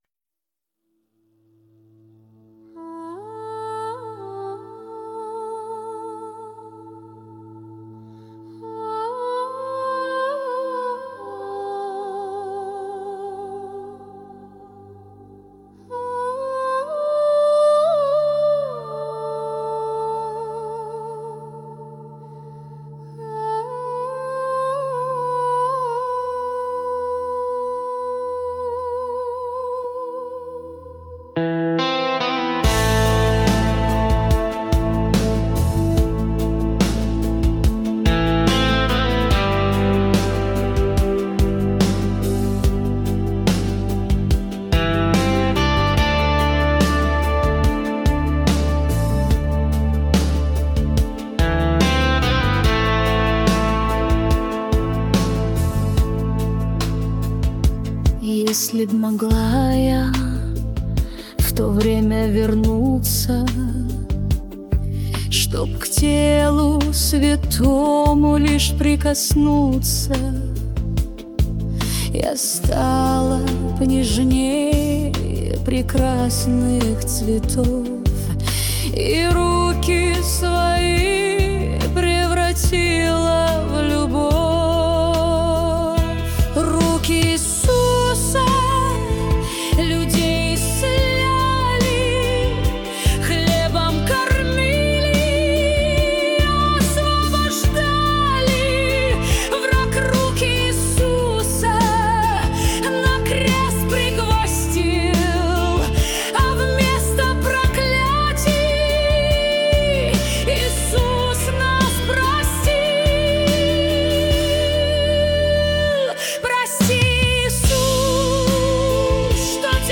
песня ai
205 просмотров 632 прослушивания 71 скачиваний BPM: 71